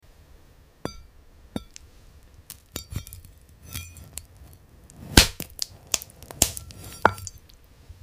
Cutting tiny watermelon for best sound effects free download
Cutting tiny watermelon for best asmr